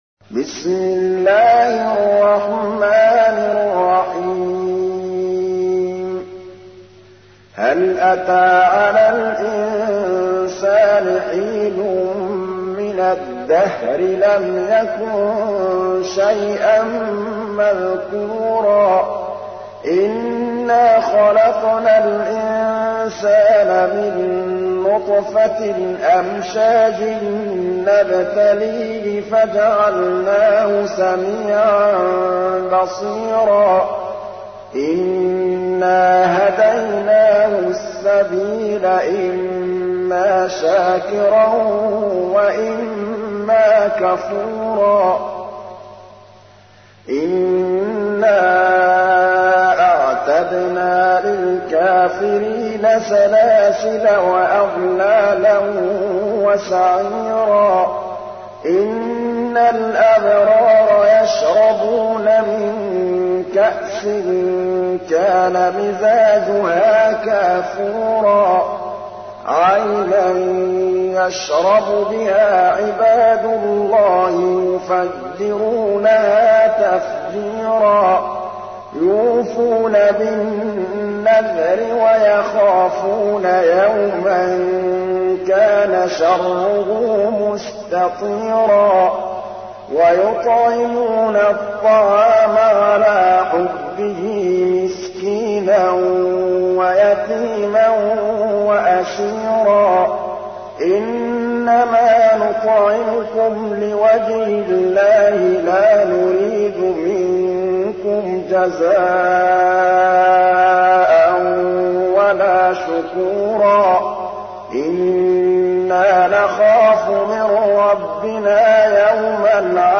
تحميل : 76. سورة الإنسان / القارئ محمود الطبلاوي / القرآن الكريم / موقع يا حسين